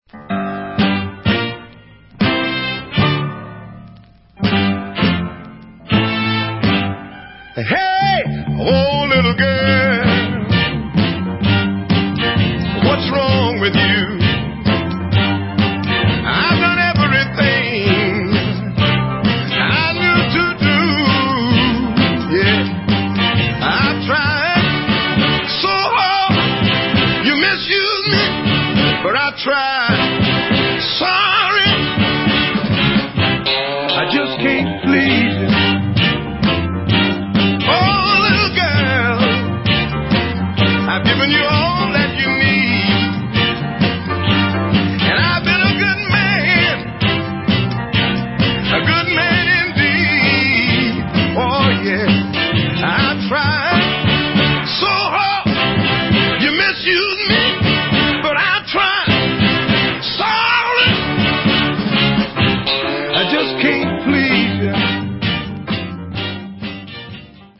60's Northern Soul CLASSIC